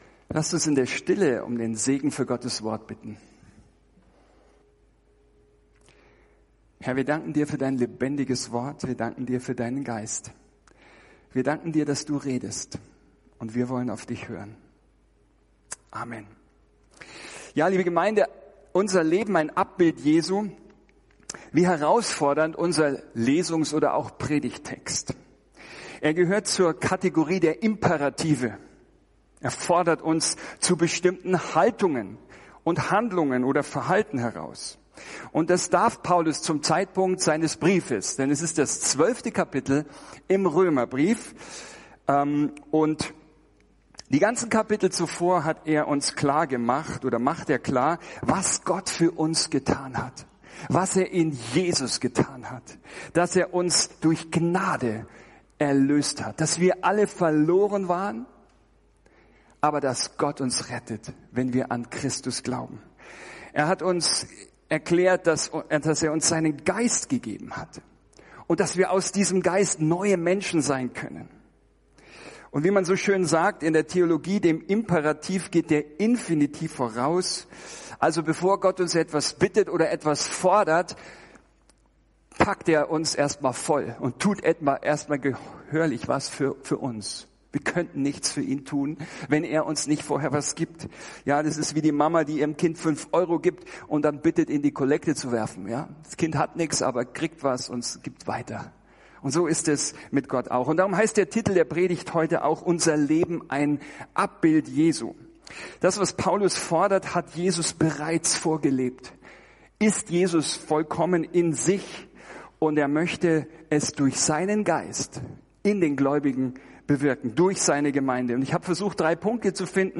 Ein Studienblatt zur Predigt ist im Ordner “Notizen” (Dateien zum Herunterladen) verfügbar